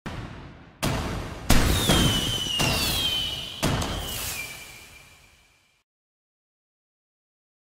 جلوه های صوتی
دانلود صدای آتش بازی 1 از ساعد نیوز با لینک مستقیم و کیفیت بالا